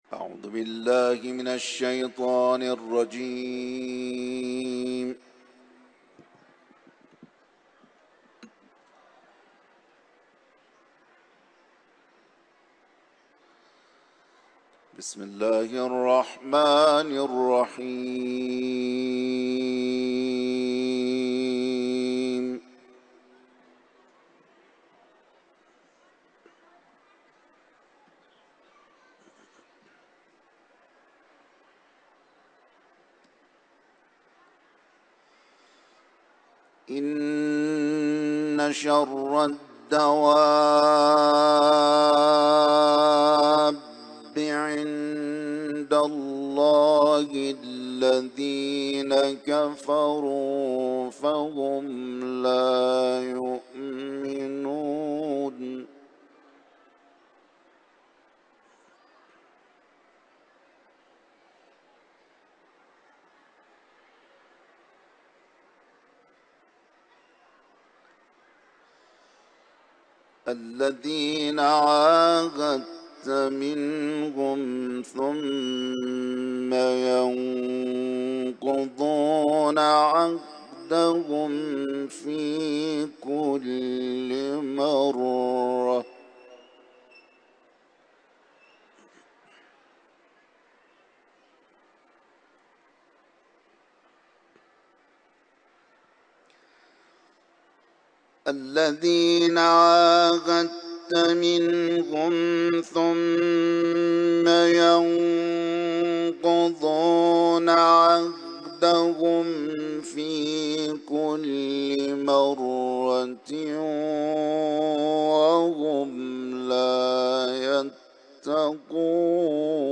تلاوت قرآن ، سوره انفال